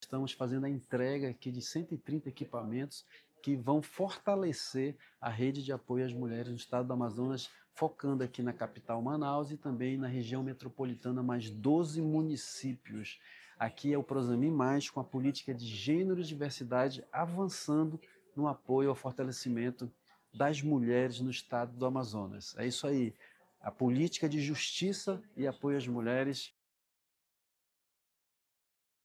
UGPE_SONORA-SECRETARIO-DA-SEDURB-E-DA-UGPE-MARCELLUS-CAMPELO-1.mp3